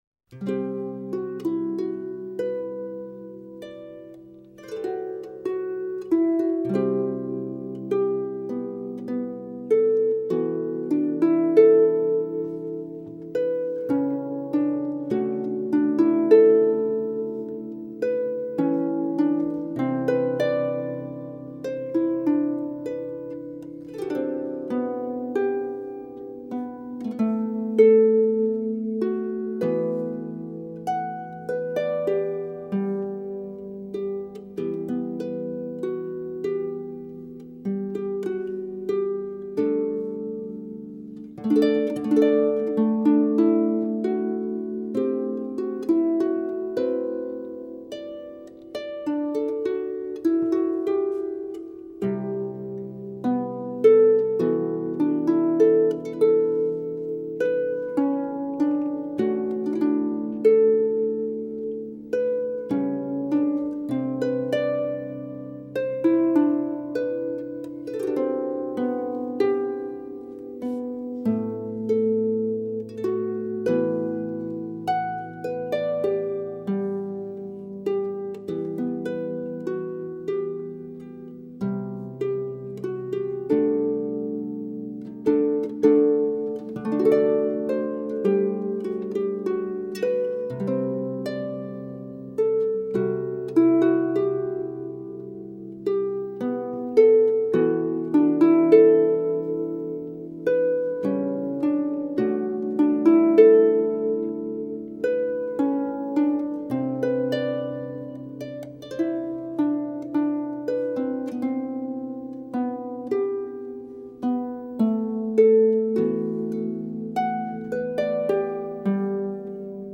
music Medieval times
Harp music
harfe.mp3